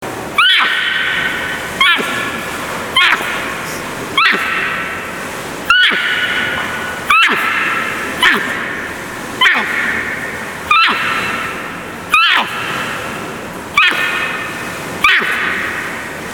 ボイスレコーダーで撮っただけのもので、水の流れる音も煩いと思いますが御了承くださいませ。
この声は、子供が母親を呼ぶ時に出す声に近いそうです。合図で鳴いてもらっています。
微妙に普段と違うのですが・・・。